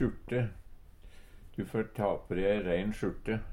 sjurte - Numedalsmål (en-US)